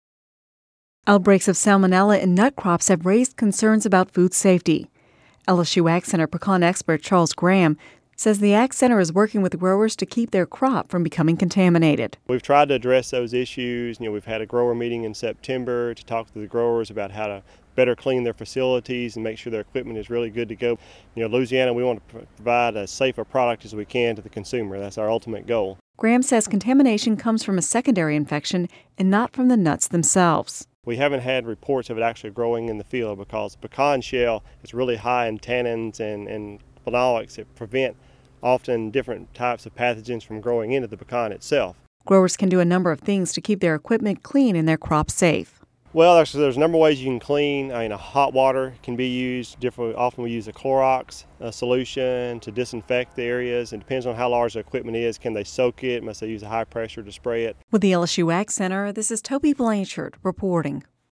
(Radio News 11/08/10) Outbreaks of salmonella in nut crops have raised concerns about food safety.